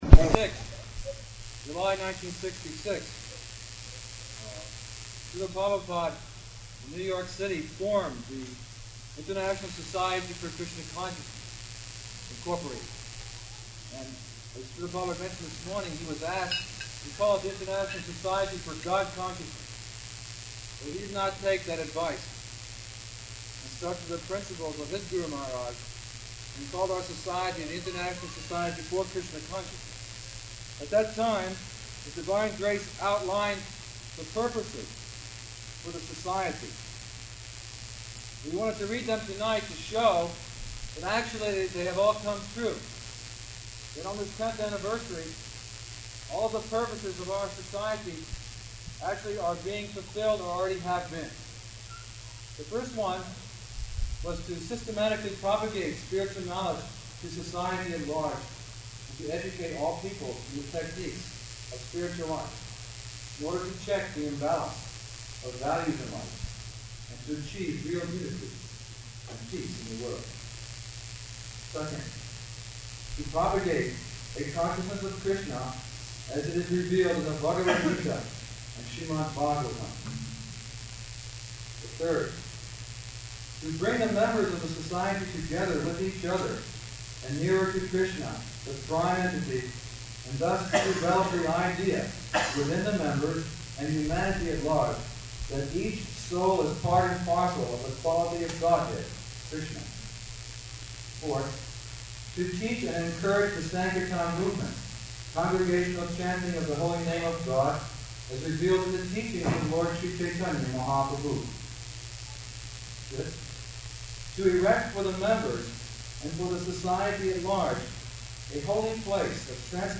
Type: Lectures and Addresses
Location: Washington, D.C.